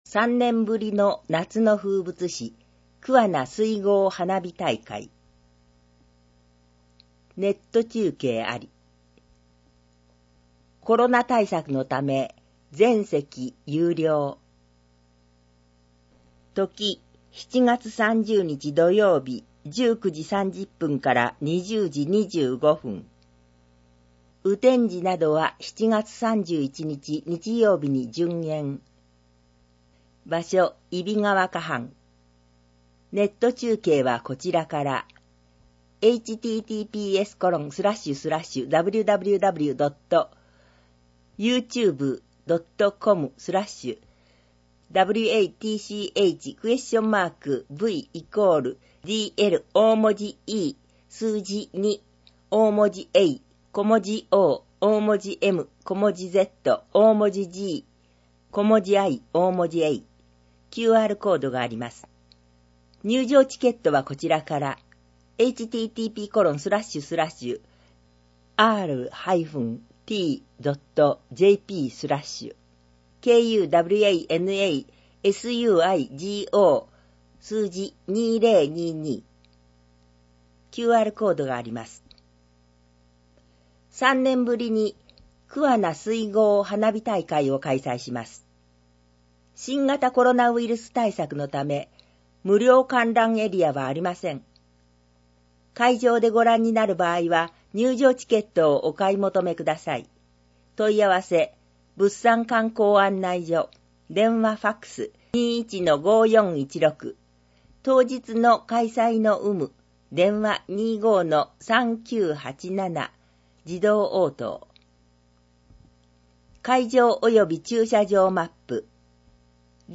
なお、「声の広報くわな」は桑名市社会福祉協議会のボランティアグループ「桑名録音奉仕の会」の協力で制作しています。